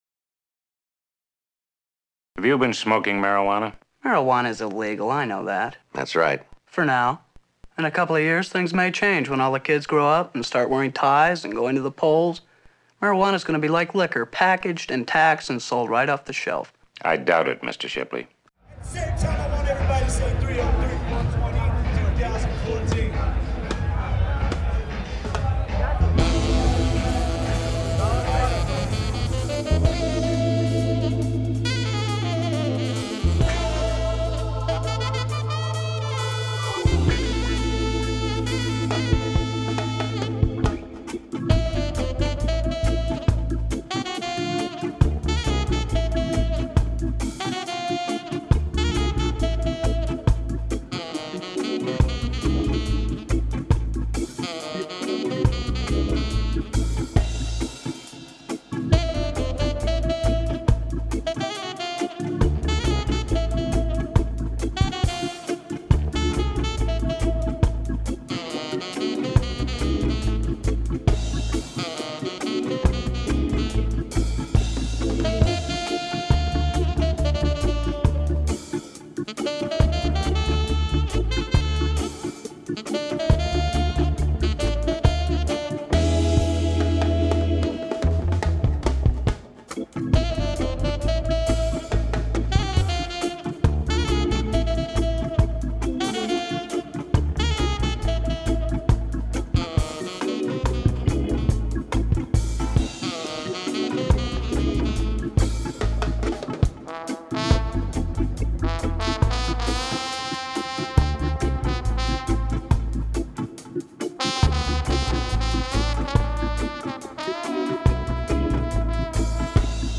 For the first time ever, the annual event was a celebration of marijuana legalization rather than a protest against a failed policy. Reason TV brought cameras to the event.